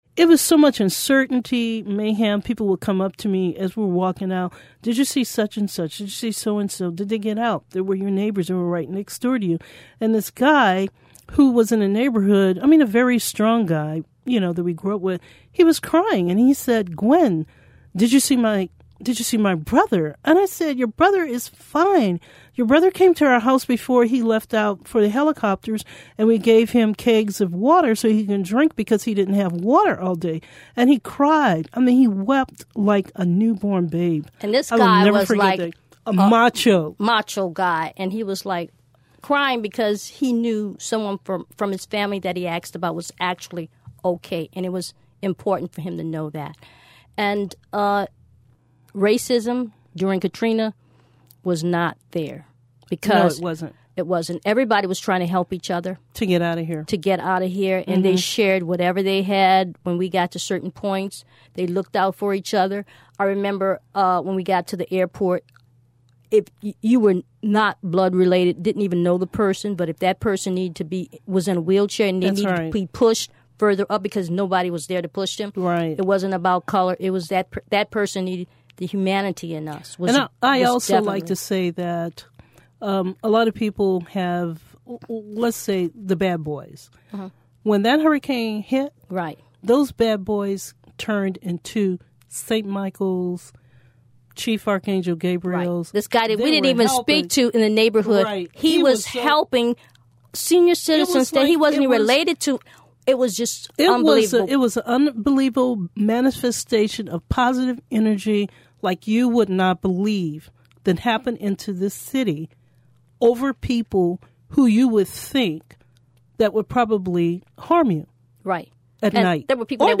This interview was recorded at StoryCorps’ mobile studio in New Orleans.